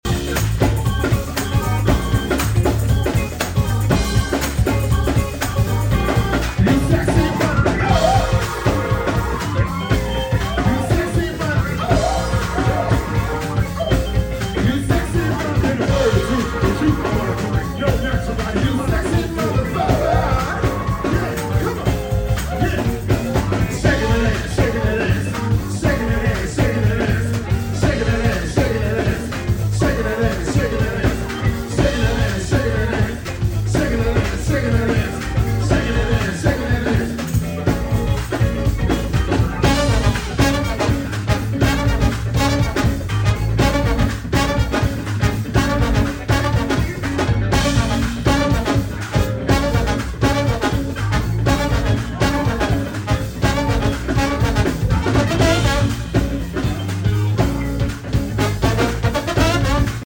trombone player
Such a New Orleans moment!